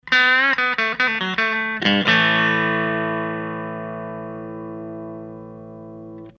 で、いつもの安いトランジスタアンプで音出しです。
DISTORTION WOLF OFF(77kbMP3)